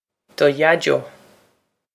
duh yaj-oh
This is an approximate phonetic pronunciation of the phrase.